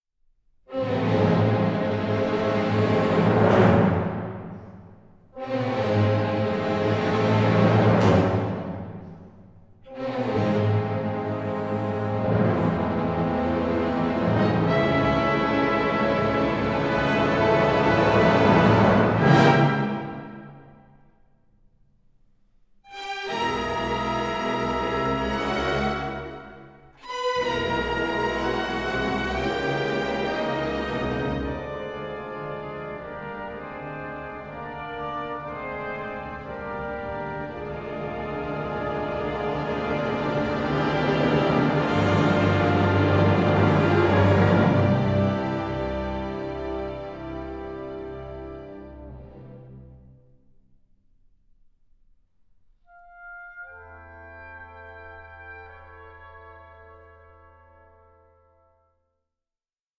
Moderato 5:19